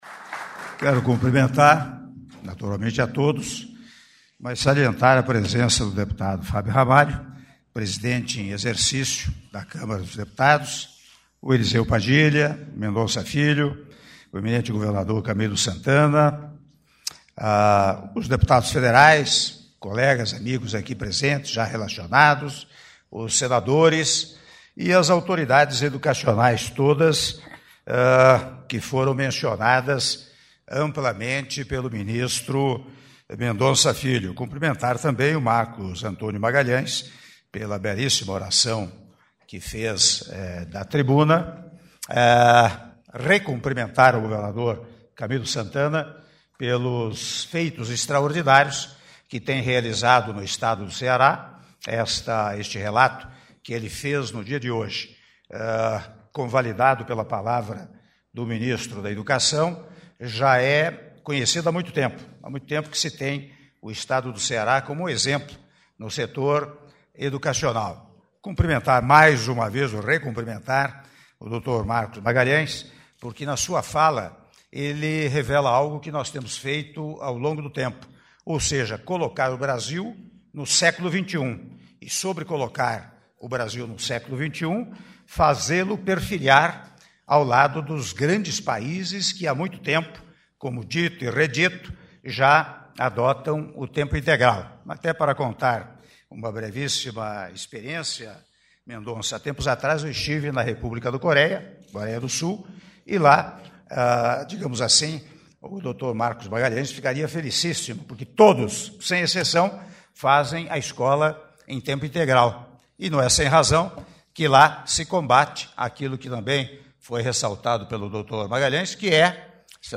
Áudio do discurso do Presidente da República, Michel Temer, durante Cerimônia de Liberação de Recursos para o Ensino Médio de Tempo Integral - (06min58s) - Brasília/DF